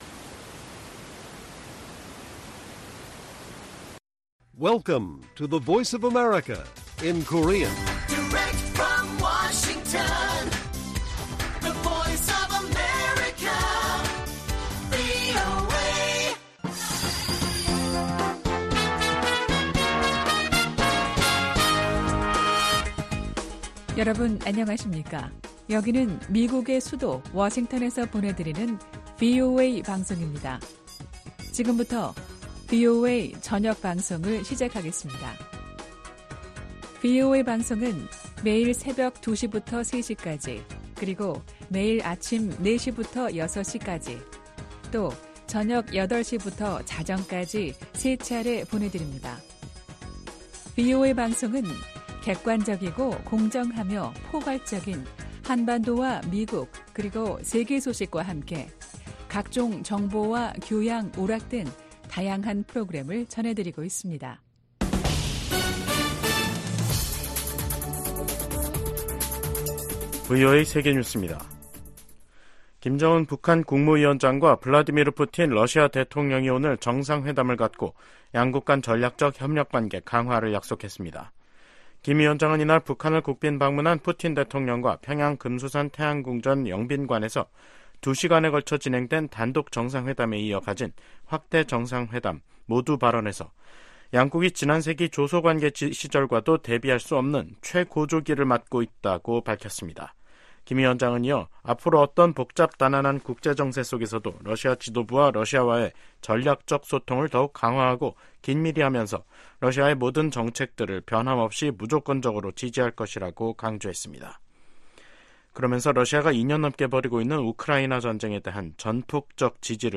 VOA 한국어 간판 뉴스 프로그램 '뉴스 투데이', 2024년 6월 19일 1부 방송입니다. 김정은 북한 국무위원장과 블라디미르 푸틴 러시아 대통령이 오늘, 19일 평양에서 정상회담을 갖고 포괄적 전략 동반자 협정에 서명했습니다. 미국 백악관은 푸틴 러시아 대통령이 김정은 북한 국무위원장에게 외교가 한반도 문제 해결의 유일한 해법이라는 메시지를 전달해야 한다고 촉구했습니다.